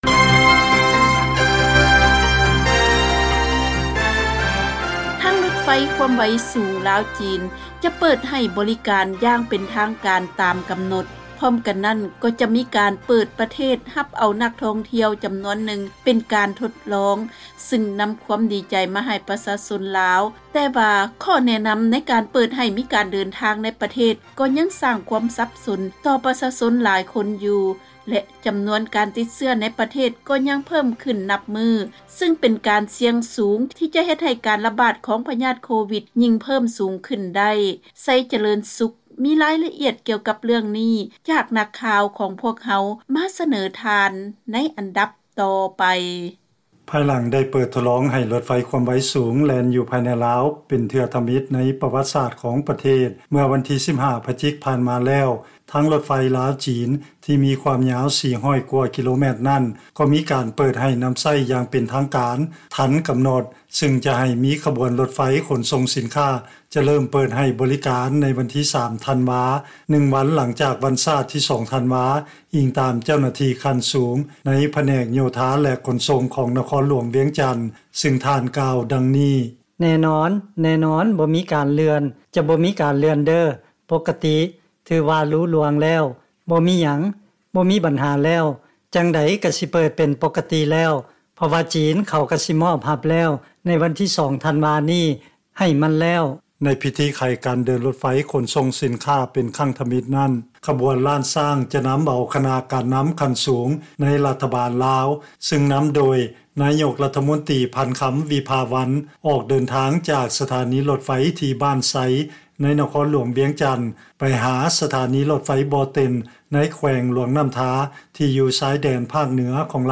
ເຊີນຟັງລາຍງານ ພິທີໄຂການເດີນລົດໄຟຄວາມໄວສູງ ລາວ-ຈີນ ຢ່າງເປັນທາງການ ໃນວັນທີ 3 ທັນວາ ຕາມແຜນການທີ່ໄດ້ວາງໄວ້